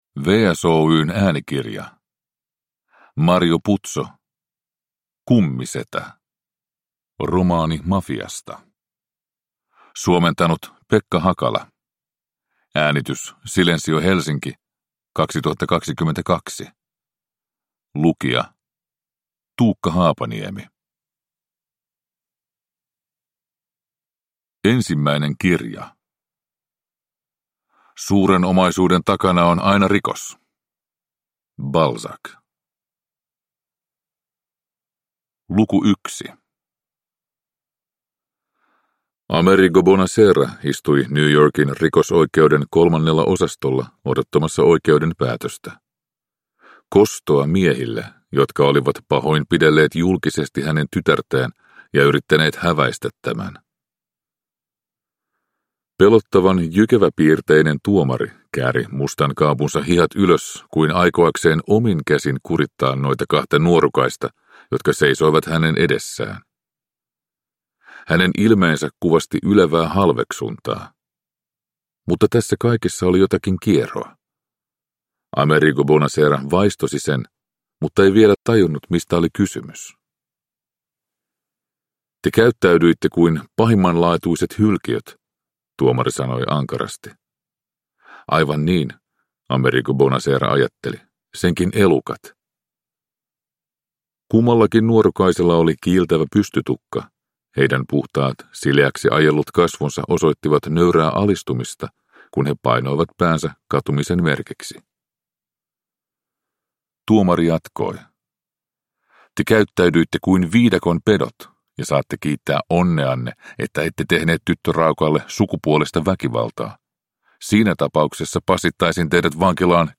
Kummisetä – Ljudbok – Laddas ner